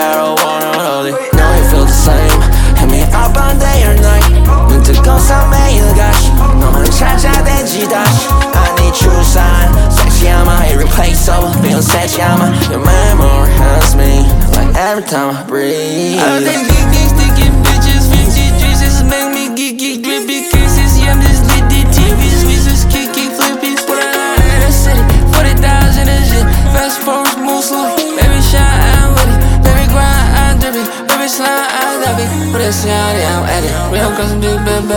Hip-Hop Rap Pop K-Pop
Жанр: Хип-Хоп / Рэп / Поп музыка